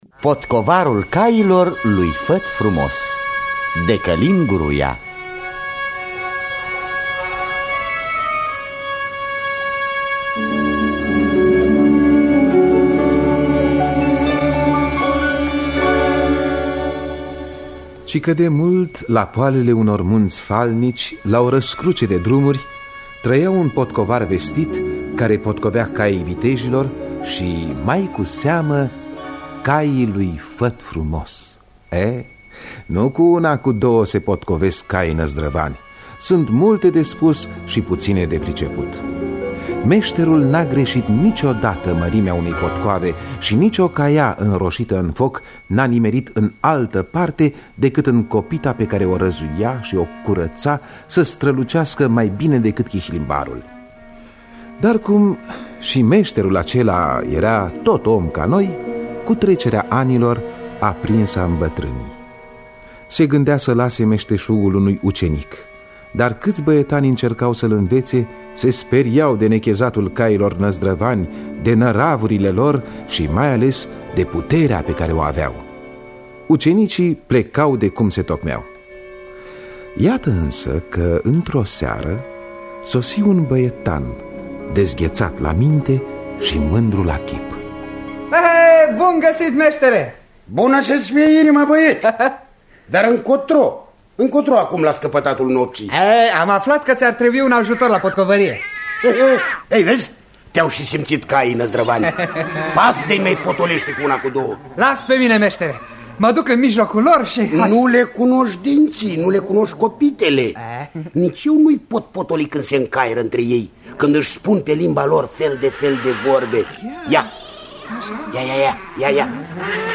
Scenariu radiofonic de Călin Gruia.